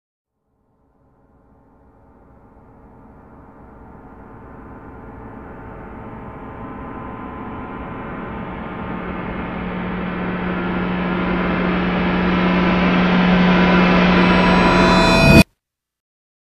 Intense Dramatic Sound Effect Free Download
Intense Dramatic